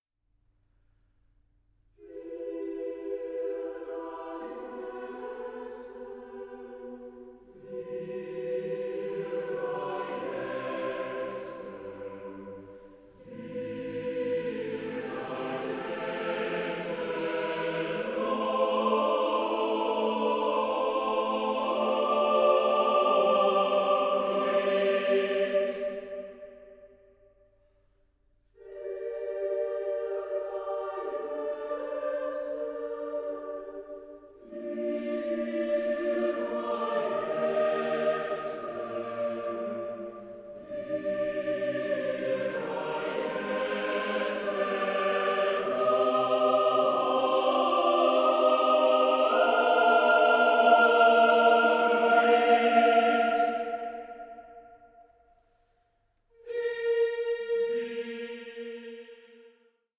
Graduale
TWO MASTERS OF AUSTRIAN CHURCH MUSIC COMBINED